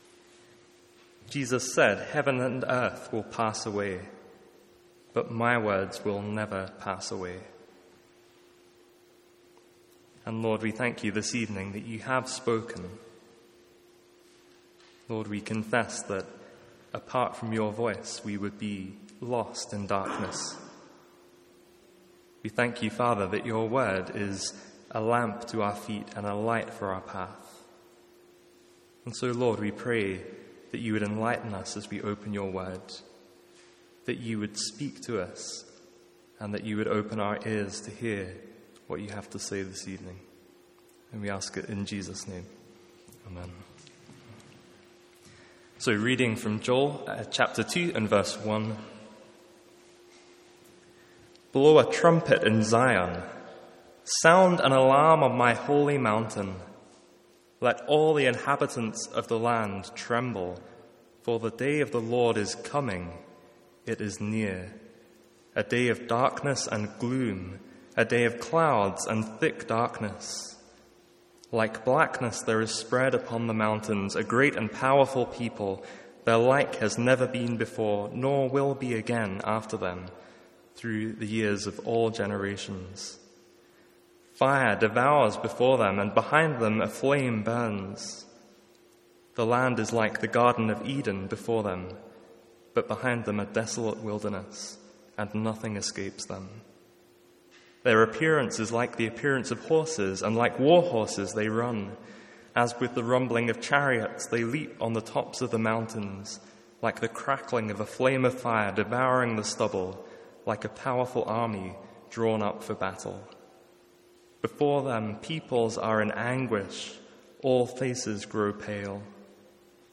Sermons | St Andrews Free Church
From our evening series in Joel.